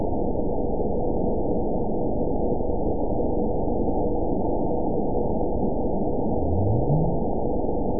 event 920025 date 02/09/24 time 03:45:58 GMT (1 year, 8 months ago) score 9.67 location TSS-AB01 detected by nrw target species NRW annotations +NRW Spectrogram: Frequency (kHz) vs. Time (s) audio not available .wav